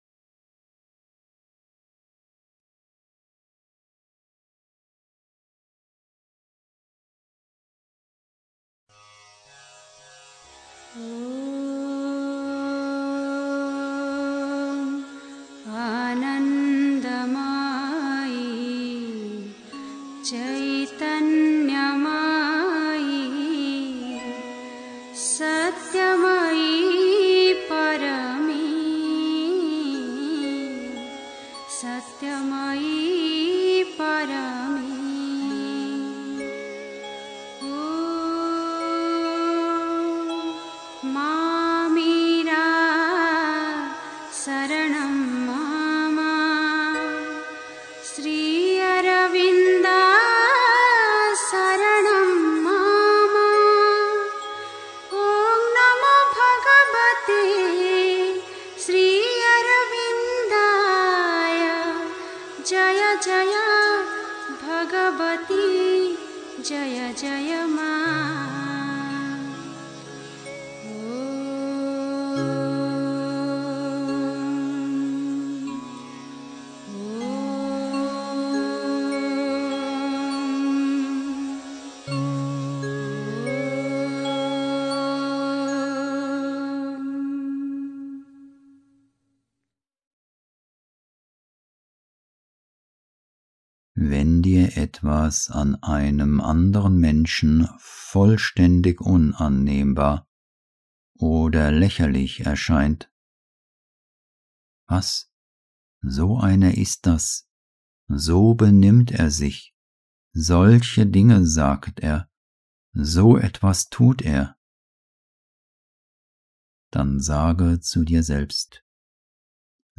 1. Einstimmung mit Musik. 2. Andere sind ein Spiegel (Die Mutter, The Sunlit Path) 3. Zwölf Minuten Stille.